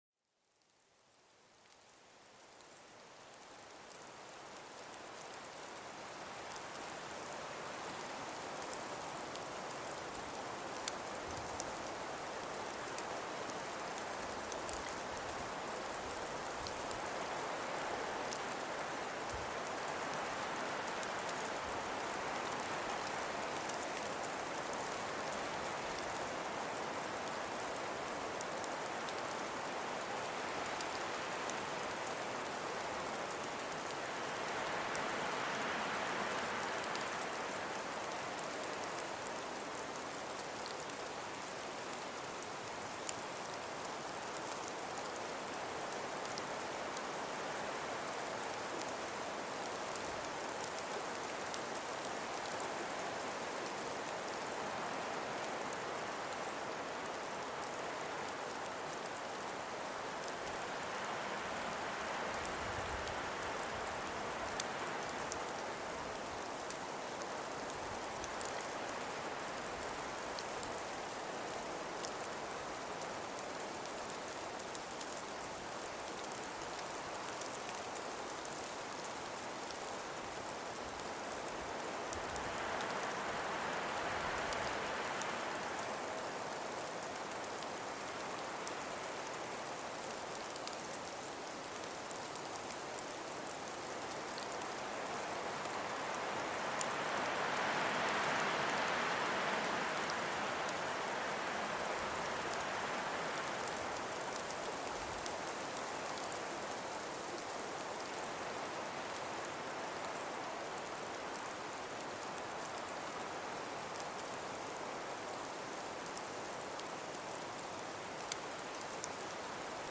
BERGDORF-ZAUBER: Schneeklänge als natürliches Schlafmittel entlarvt
Naturgeräusche